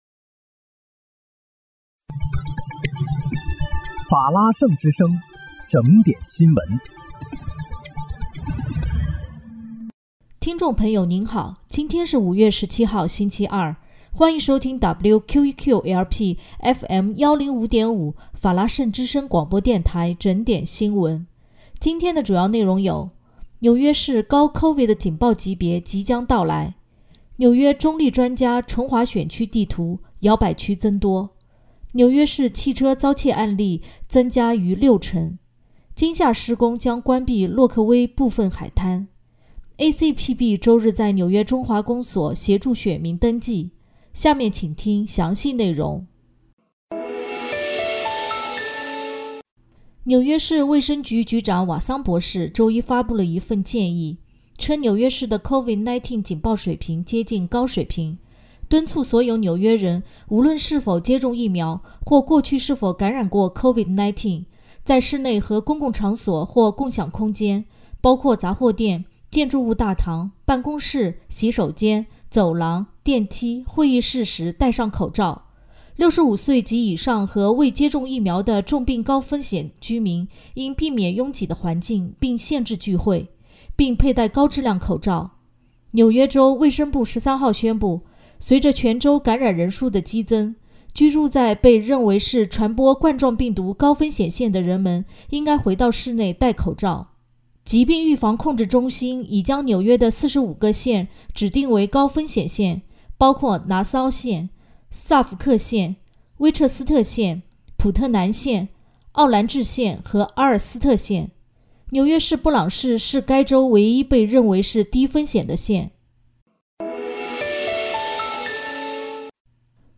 5月17日（星期二）纽约整点新闻